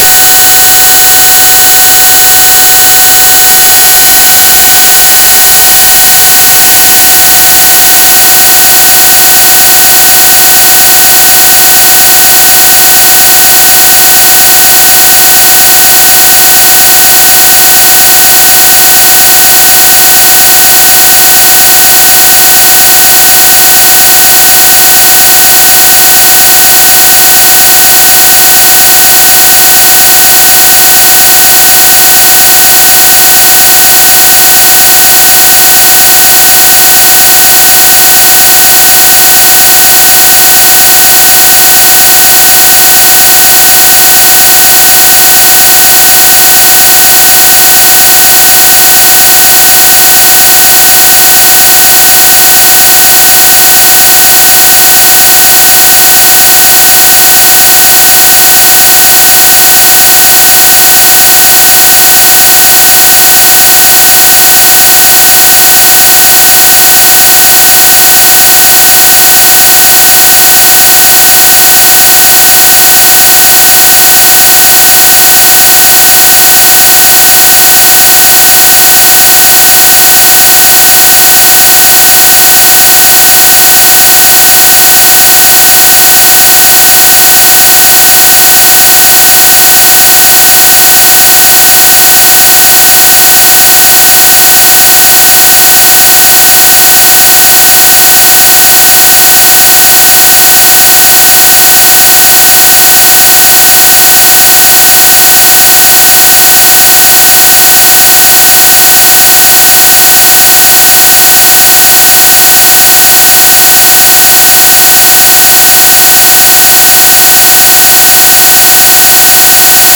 marge i tried converting 'G into audio and it's giving me a weird static sound
>left: 'G converted to audio
>right: raw 'G. EARRAPE WARNING